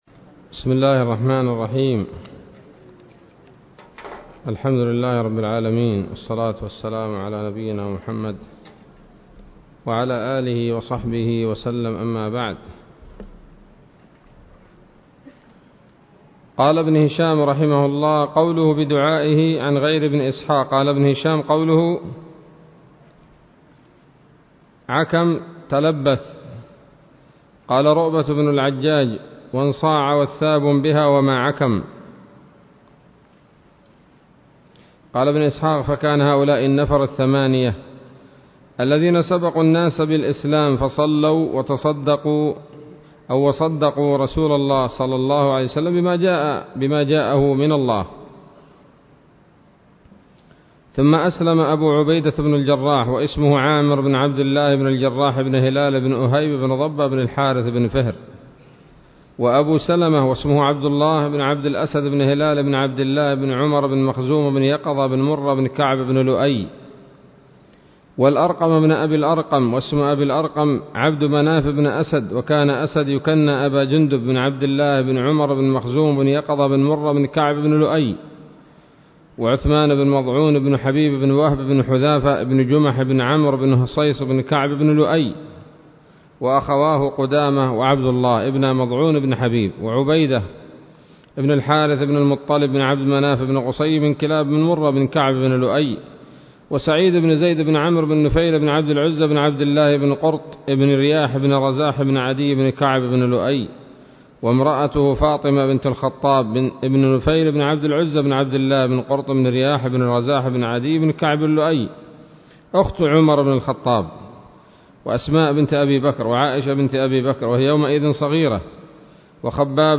الدرس السادس والعشرون من التعليق على كتاب السيرة النبوية لابن هشام